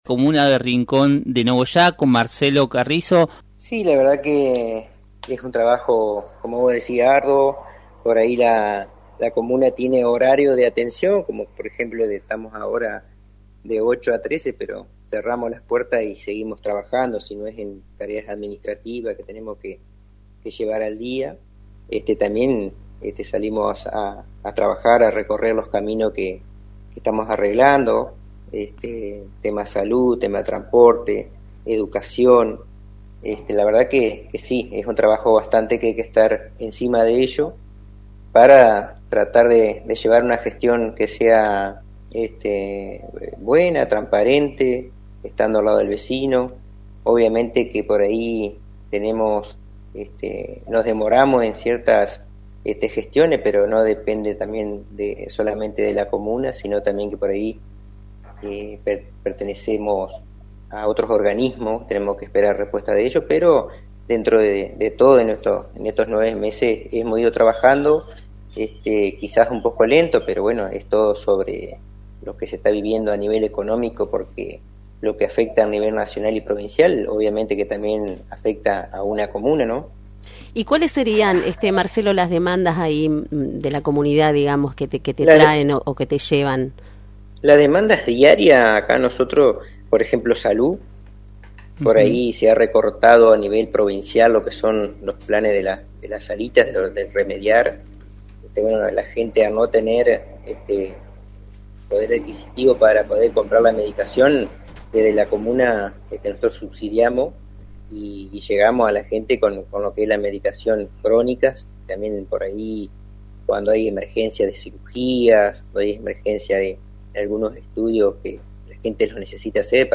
Marcelo Carrizo, presidente de la comuna de Rincón de Nogoyá, abordó diversos temas relacionados con la gestión y los desafíos que enfrenta esta pequeña localidad entrerriana.